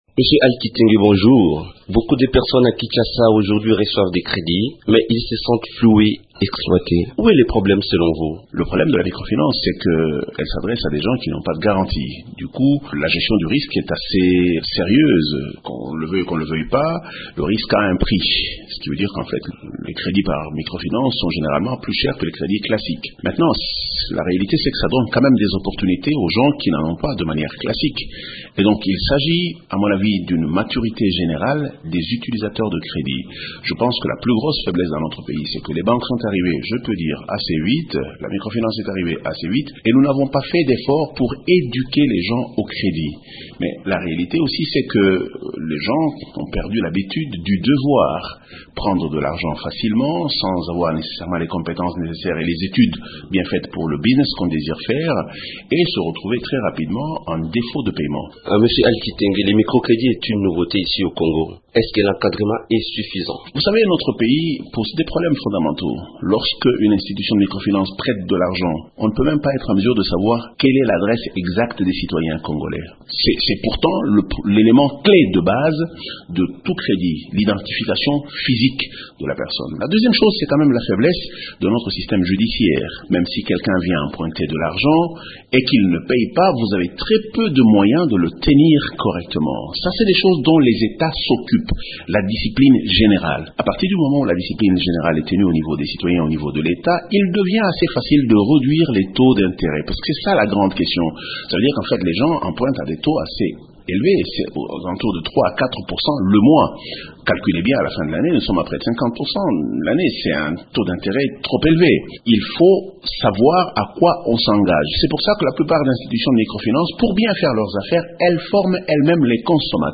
un entretien accordé à Radio Okapi